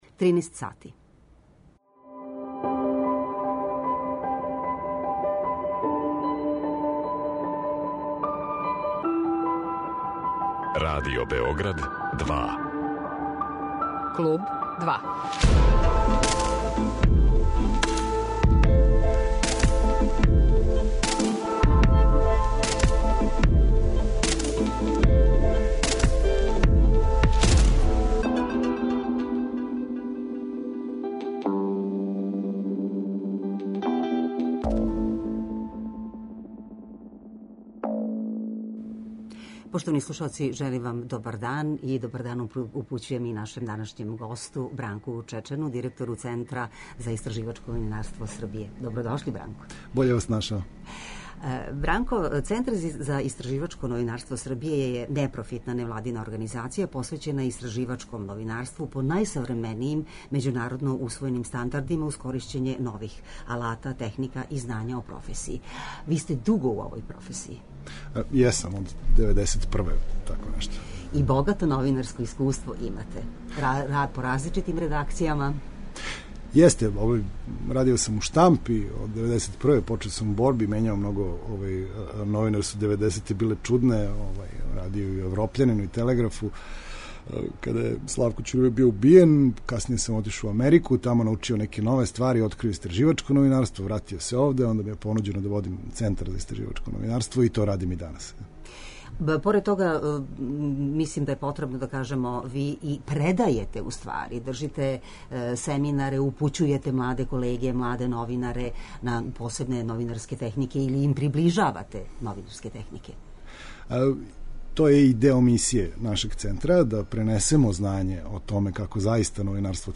Радио Београд 2